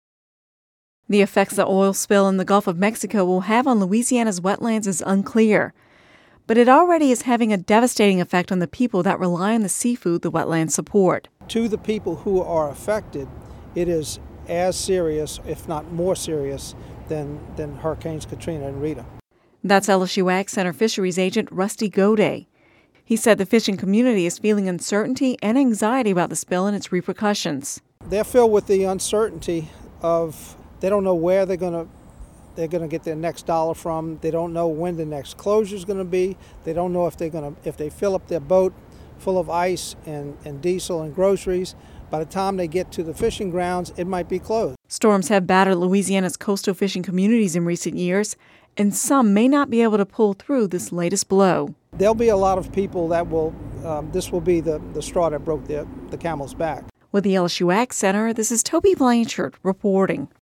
(Radio News 06/07/10) The effects the oil spill in the Gulf of Mexico will have on Louisiana’s wetlands is unclear, but it already is having a devastating effect on the people that rely on the seafood the wetlands support.